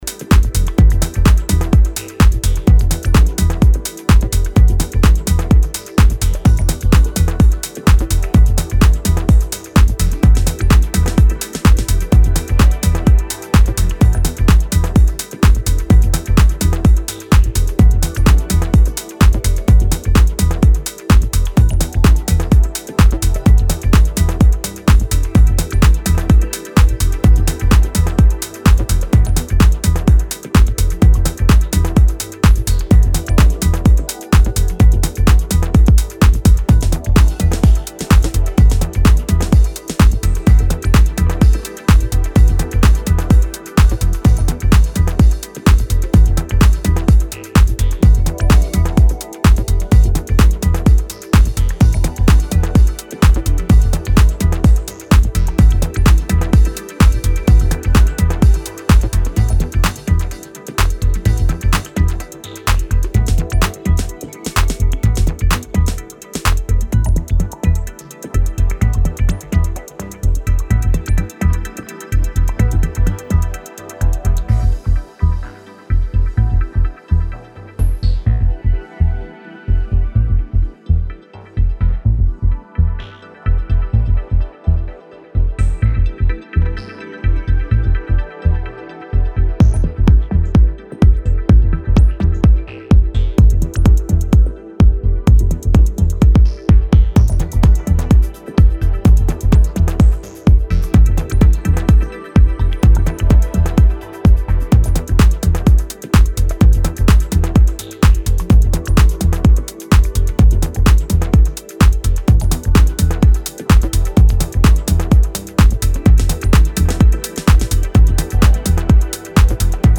minimal
presenting a warm and spacey roller.